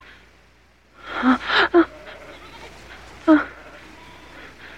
woman whimpering sound effect.ogg
Original creative-commons licensed sounds for DJ's and music producers, recorded with high quality studio microphones.
[woman-whimpering-sound-effect]_myw.mp3